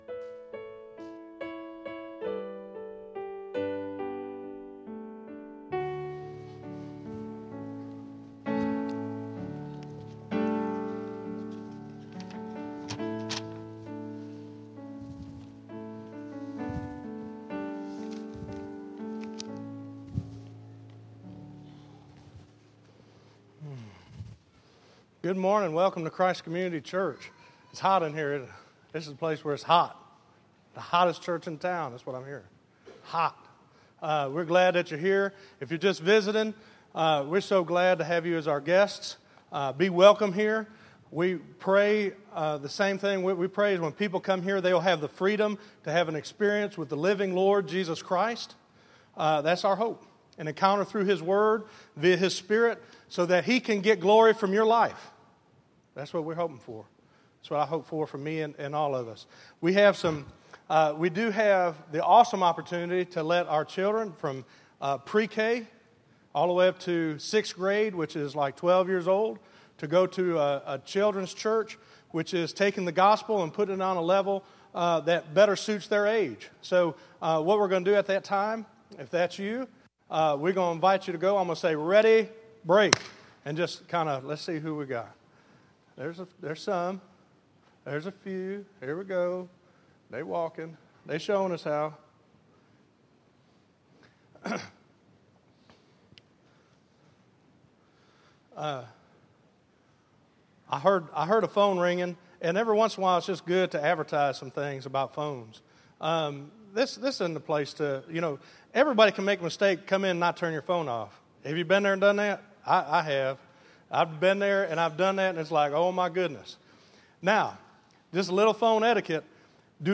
Listen to We Need to Be Revived - 01_26_14_Sermon.mp3